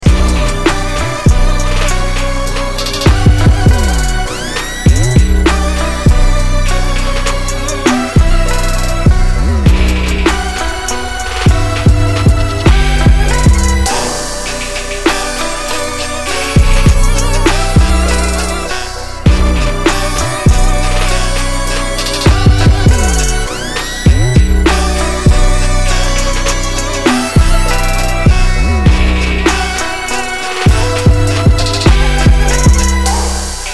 • Качество: 320, Stereo
мощные басы
Trap
instrumental hip-hop